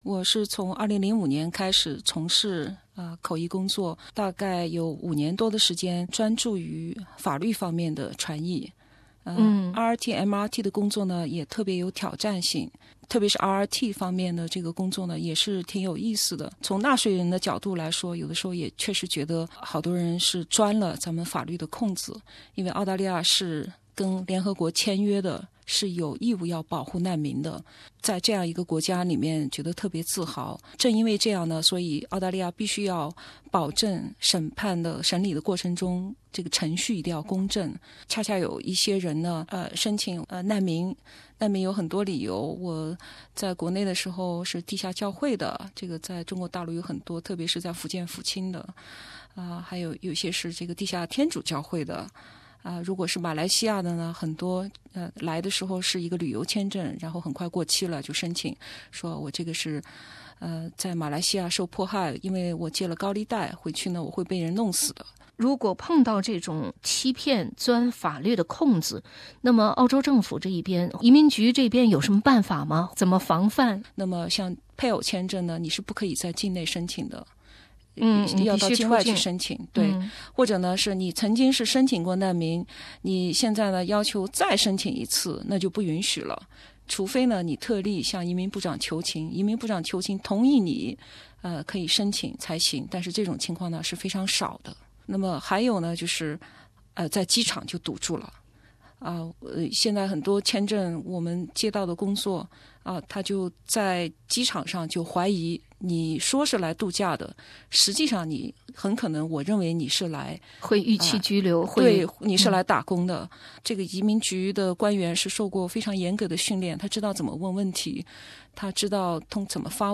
通过SBS电视台“签证诈骗”节目中的明察暗访和普通话广播的系列访谈，听众朋友可以看到社会上存在着拿澳洲身份做交易的众生相。不仅有假结婚、假家暴、还有假中介。。。